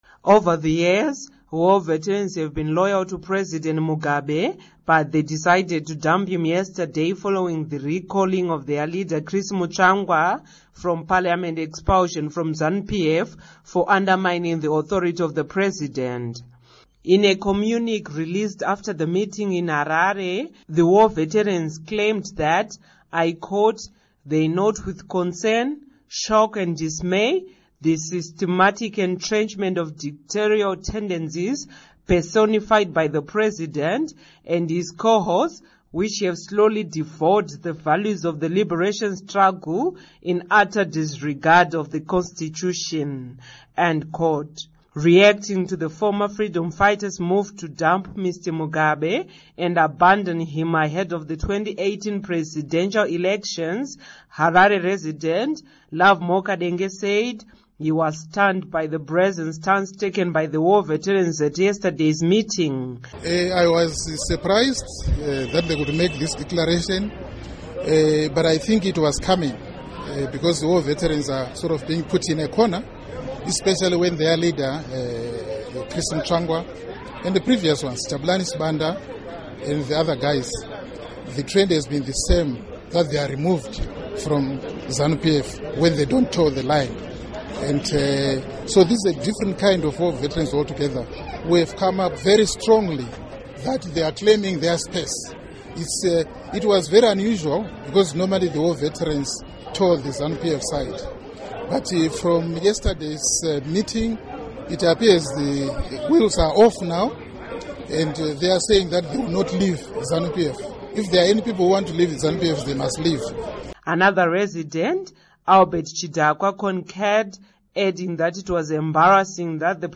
Report on War Vets